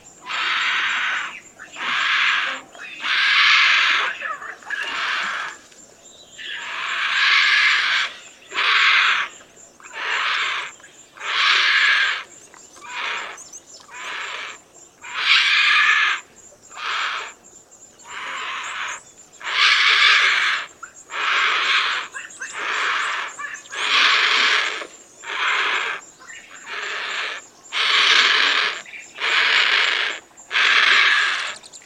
На этой странице собраны звуки грифа — мощные крики и шум крыльев одной из самых крупных хищных птиц.
Крики грифа в ночи